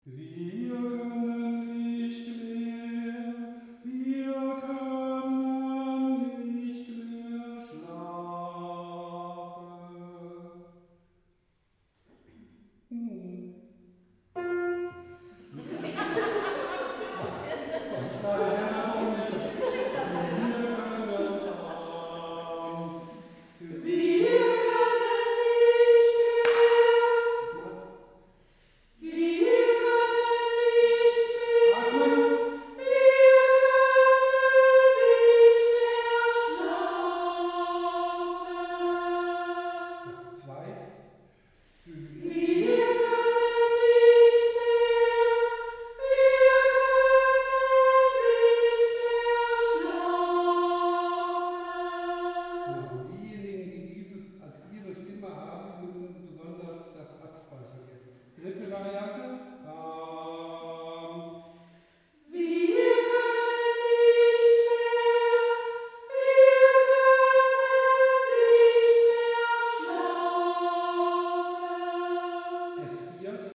Takt 35 - 42 | Einzelstimmen
Gott in uns! | T 35 | Sopran 3